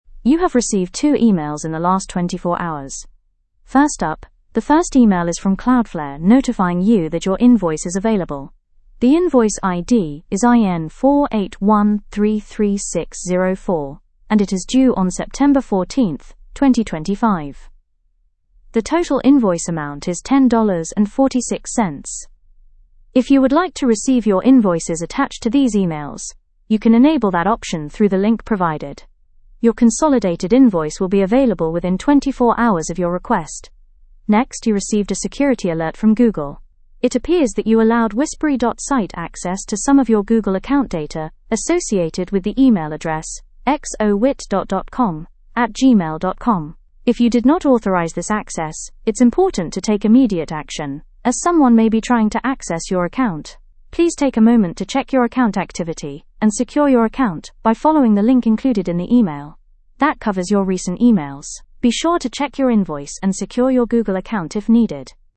It produces brief email summaries in an audio format.
I’m really surprised that the audio is so good.
You pass a text to it, and it puts breaks, intonation…
I’m using Chirp 3, which is fairly recent.